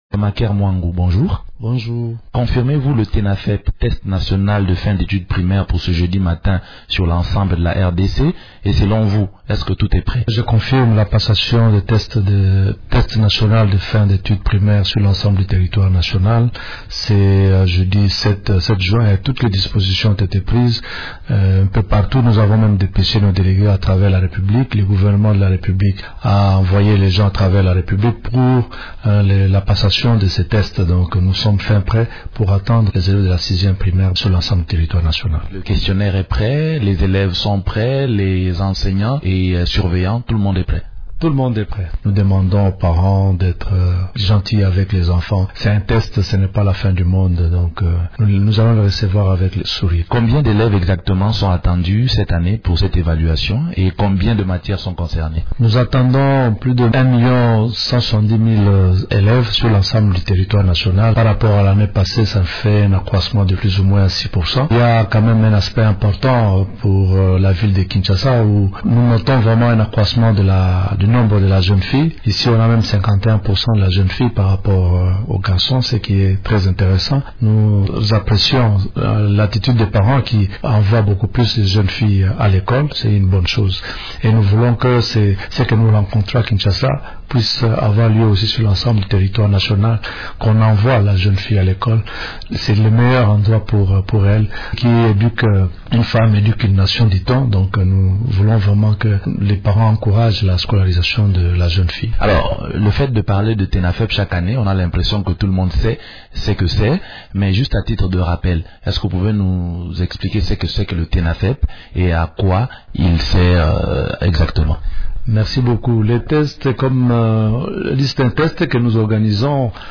Le ministre de l’Enseignement primaire, secondaire et professionnel, Maker Mwangu, est l’invité de Radio Okapi ce jeudi. Il évoque l’organisation aujourd’hui du Test national de fin d’études primaires (Tenafep).